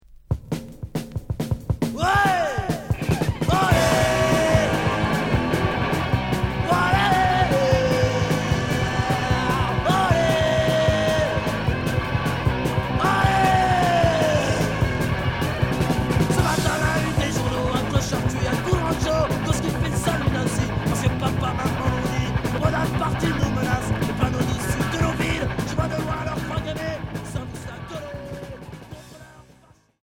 Punk rock Premier 45t